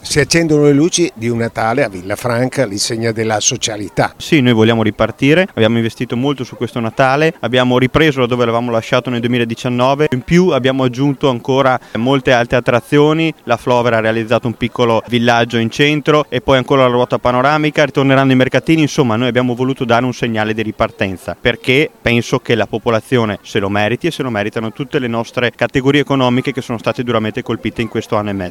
Luca Zamperini, Assessore alle Manifestazioni:
Interviste